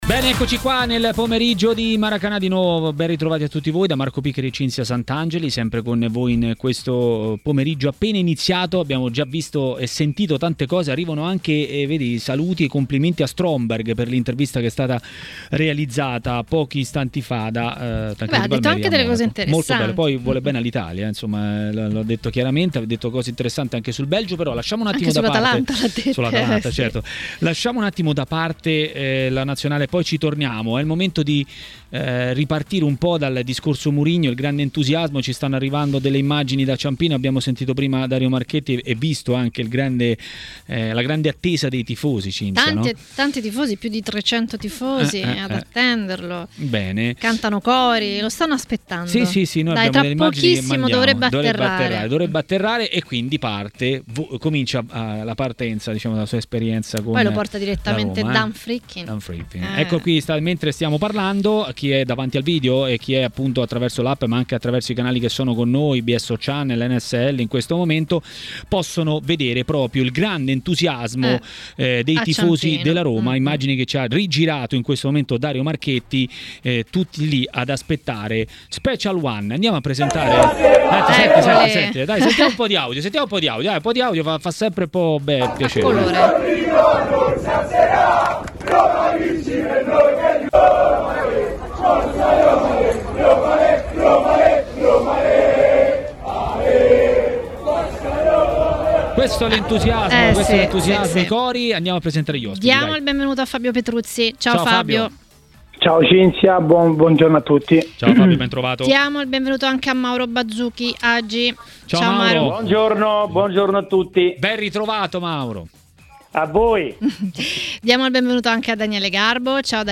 Per parlare di Roma a Maracanà, nel pomeriggio di TMW Radio, è intervenuto l'ex difensore Fabio Petruzzi.